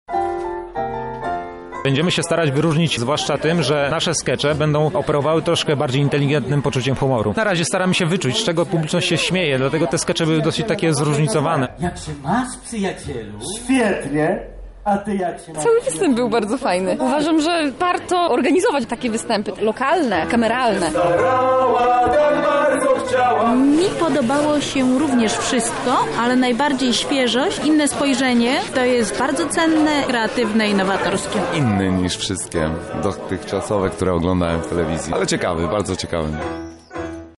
Debiutancki występ kabaretu WiR
Publiczności zaWiRowało w głowach ze śmiechu.